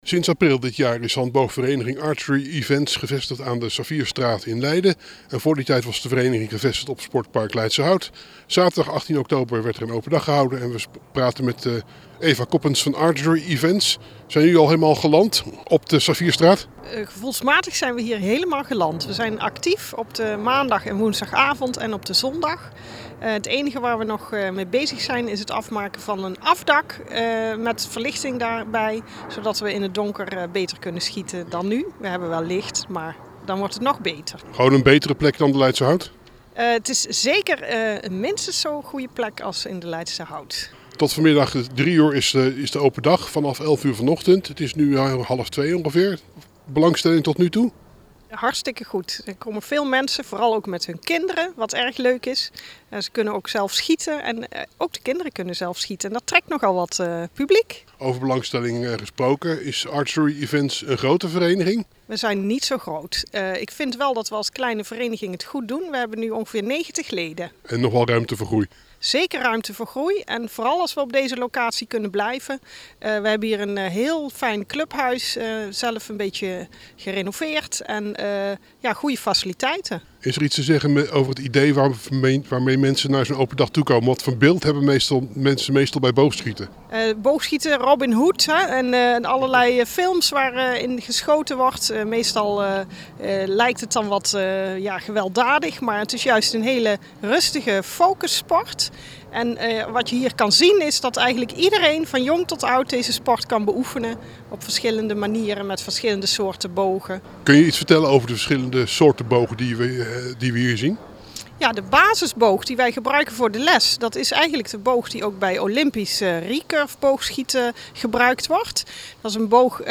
die we spraken tijdens de open dag van Archery Events.
Open-dag-Archery-Events-internet.mp3